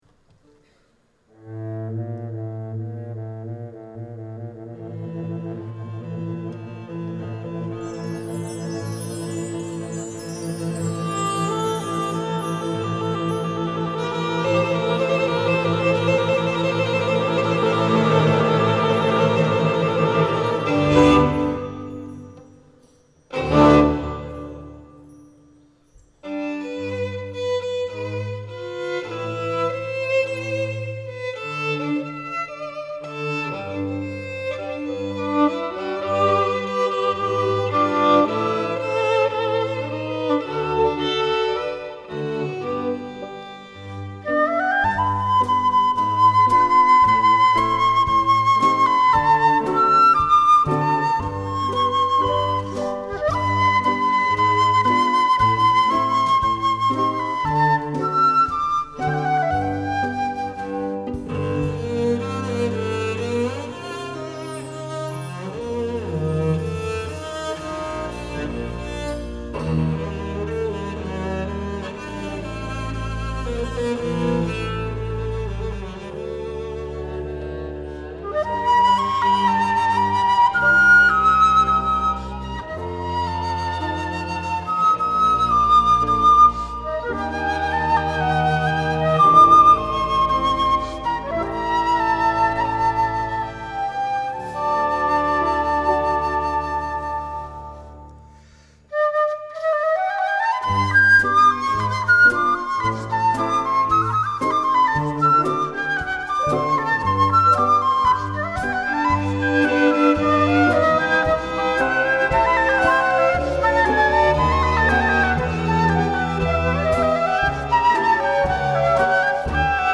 GenereMusica Classica / Cameristica